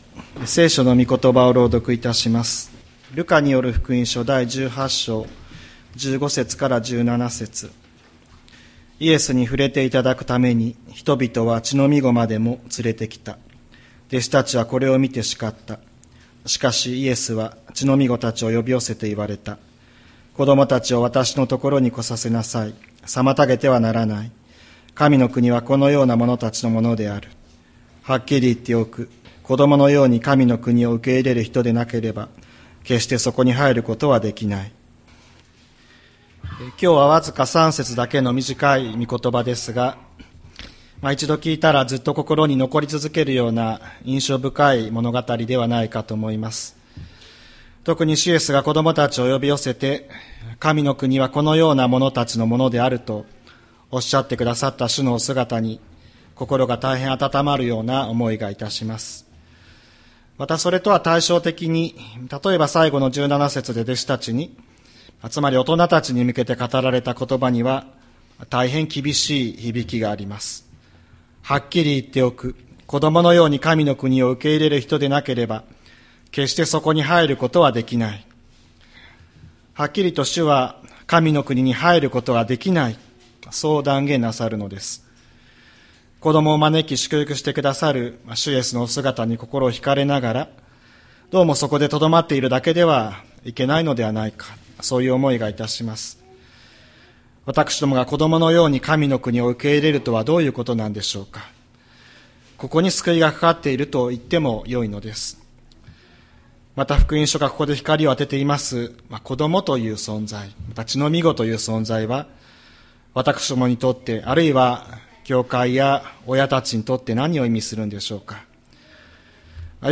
千里山教会 2025年08月24日の礼拝メッセージ。